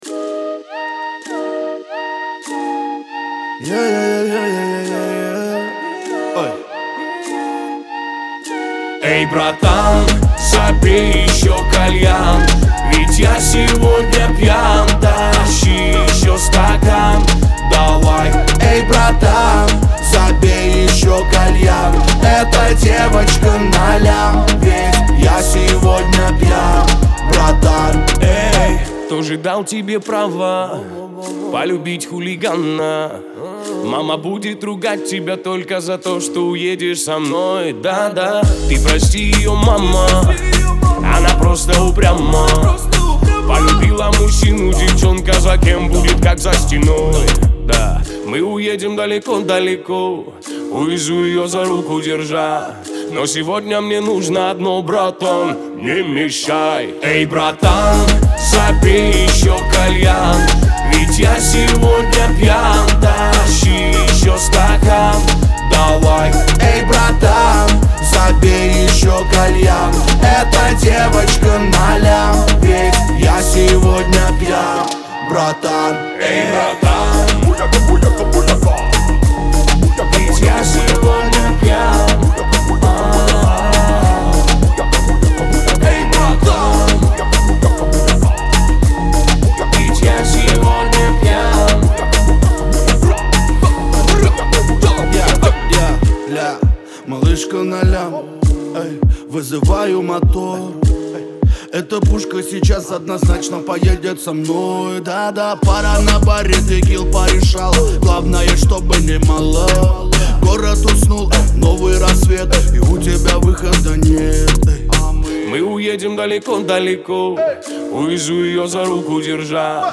это энергичная песня в жанре поп-рок